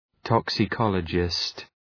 Προφορά
{,tɒksə’kɒlədʒıst} (Ουσιαστικό) ● τοξικολόγος